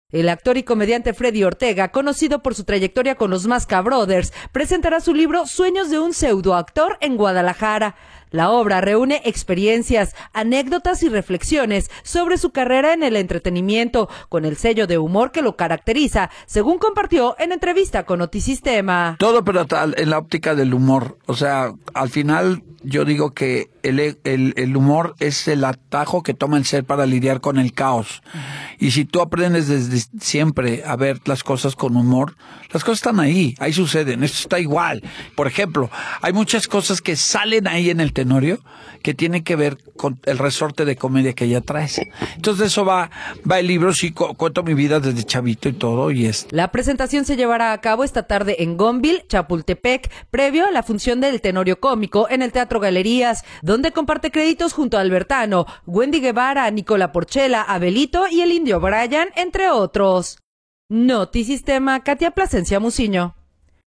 La obra reúne experiencias, anécdotas y reflexiones sobre su carrera en el entretenimiento, con el sello de humor que lo caracteriza, según compartió en entrevista con Notisistema.